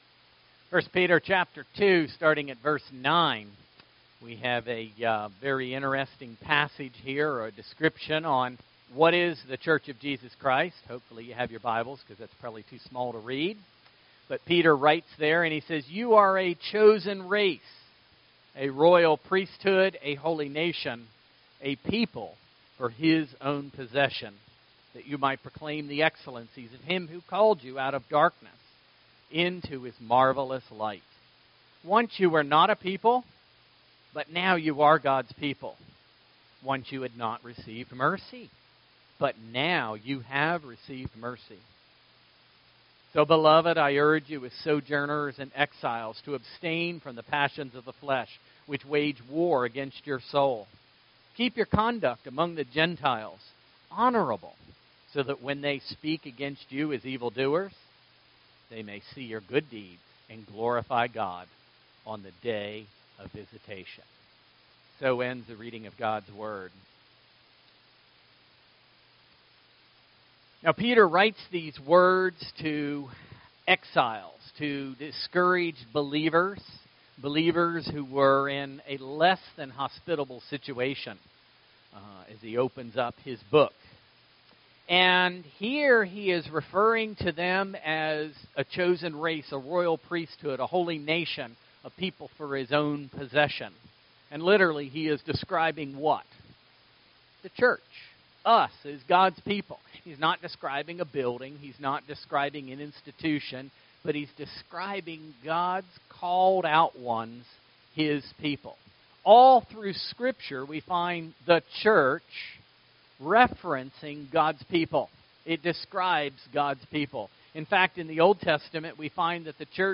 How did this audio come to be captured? Isaiah43:1-21 Service Type: Sunday Morning Worship